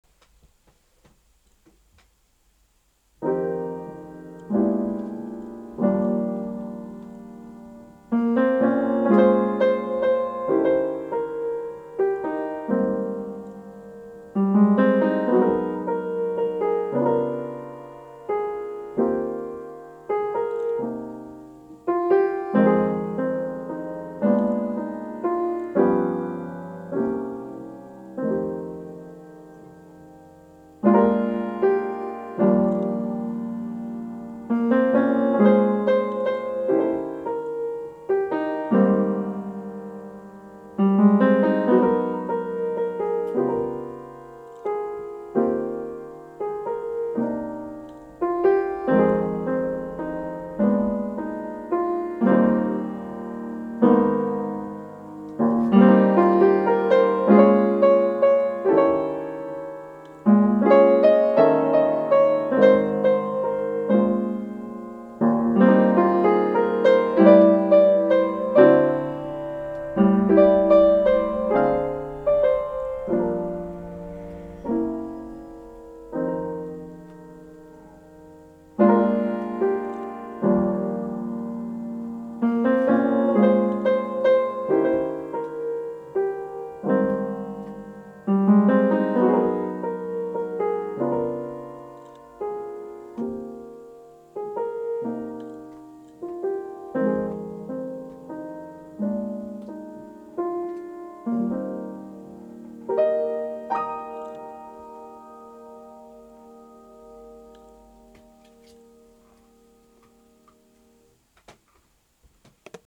mostly beginners